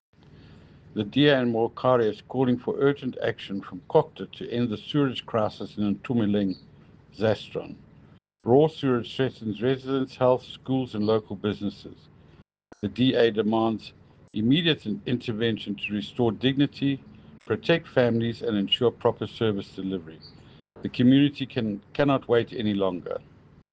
English soundbite by Cllr Ian Riddle,